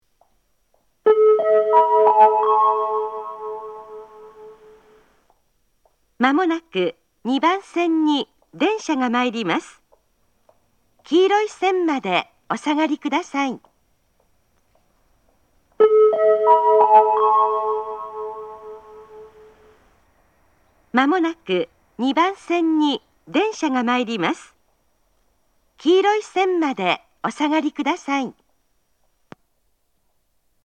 仙石旧型（女性）
仙石旧型女性の接近放送です。同じ内容を2度繰り返します。